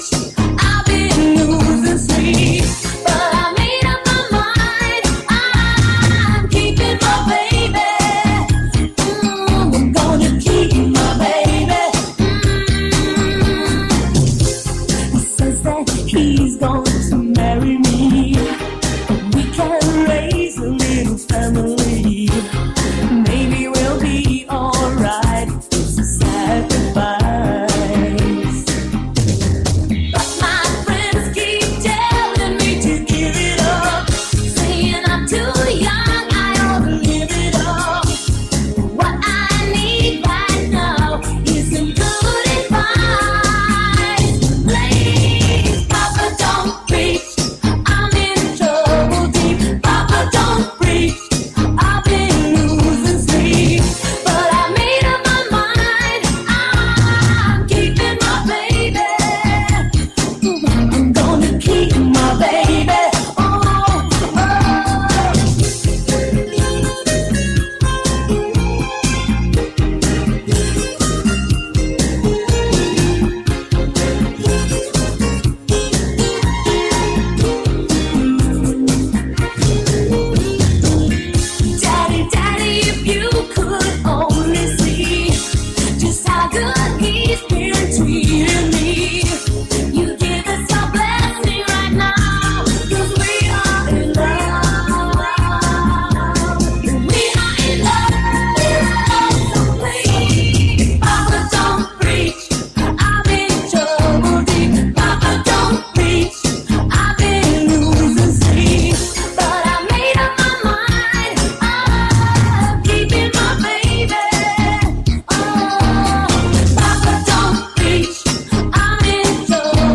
Στην εξέλιξη της φετινής τουριστικής σεζόν που θα είναι πολύ καλή αναφέρθηκε μιλώντας στον Sky σήμερα